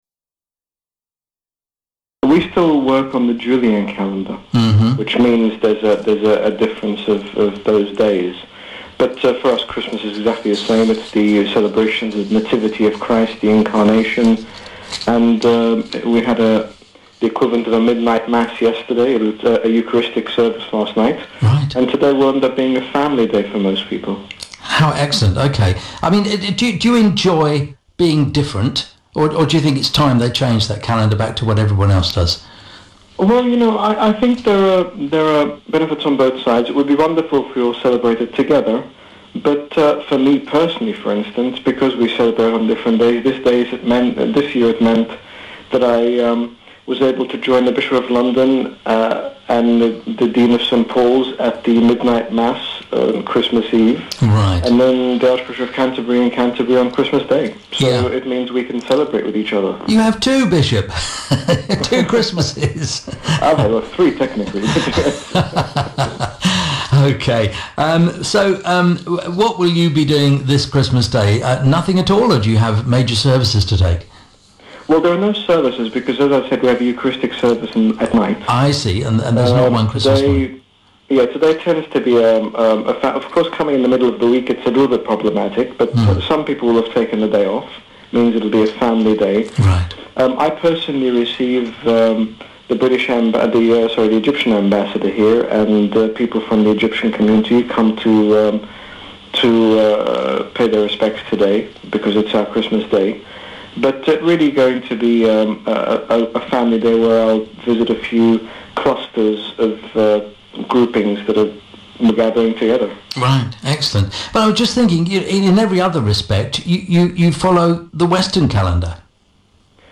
Premier Christian Radio interviews His Grace Bishop Angaelos, General Bishop of the Coptic Orthodox Church in the United Kingdom, regarding the Orthodox celebration of Christmas.